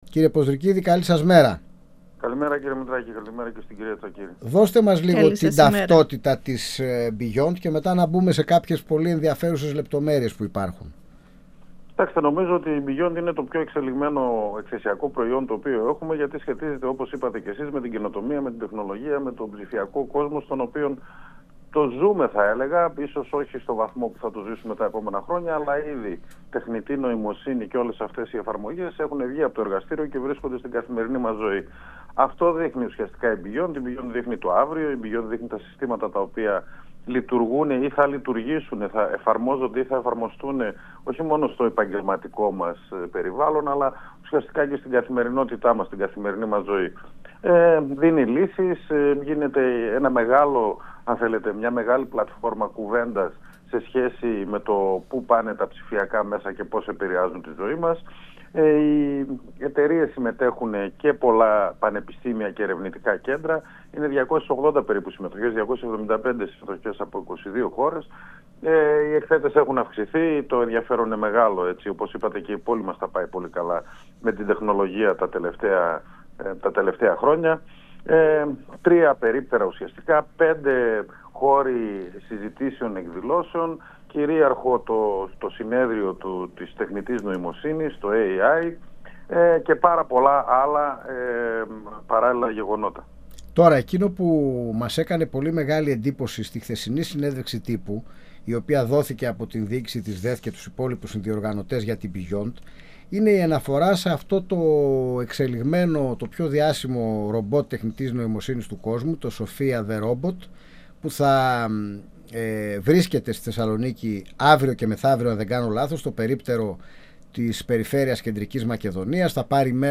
Έχει δικό της δωμάτιο σε ξενοδοχείο, μιλάει πολλές γλώσσες, γίνεται update, τη συνοδεύουν τρεις τεχνικοί, νομίζω ότι είναι κάποιος μαζί της στο δωμάτιο». 102FM Αιθουσα Συνταξης Συνεντεύξεις ΕΡΤ3